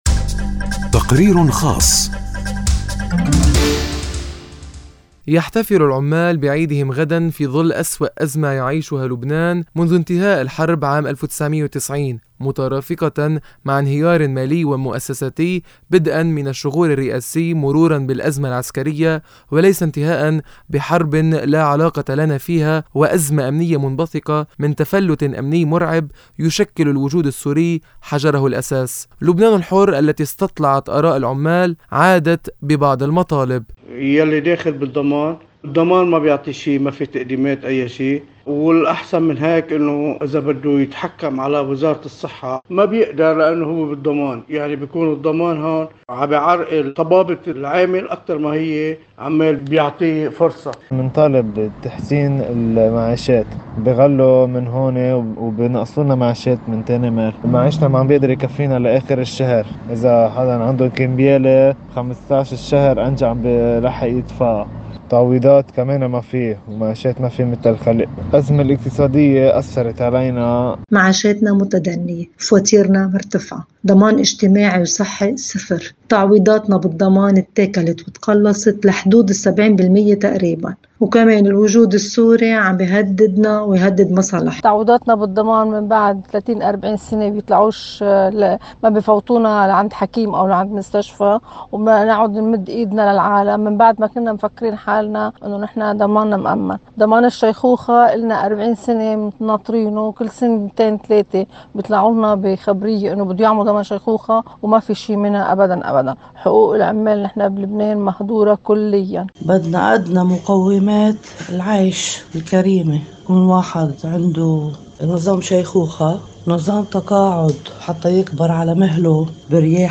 “لبنان الحرّ” التي استطلعت آراء العمّال عادت ببعض المطالب. وفي ظل ارتفاع نسبة الفَقِر في لبنان يبقى العامل الضحية الكبرى في كل الأزمات. التقرير